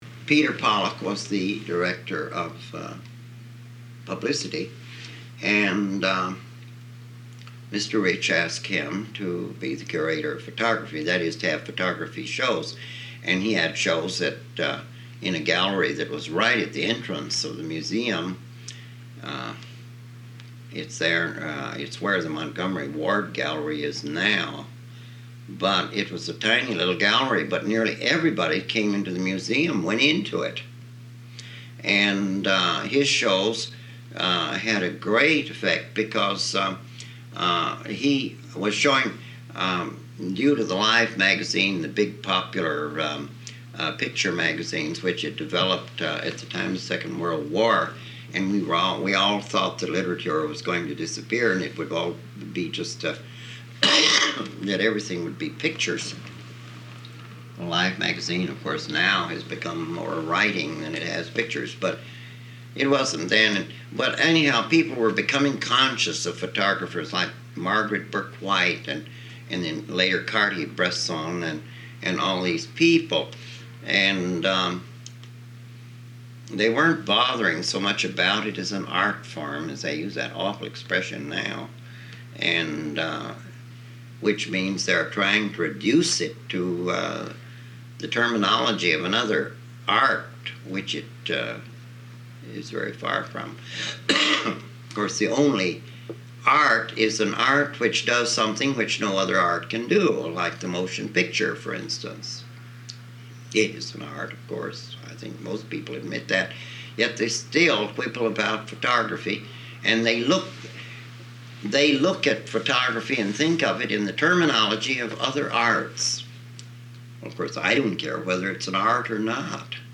He is smoking Parliament cigarettes, and you can sometimes hear the click of his lighter.
What follows is an uninterrupted eight minute section from the recordings. https